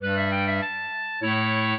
clarinet
minuet14-11.wav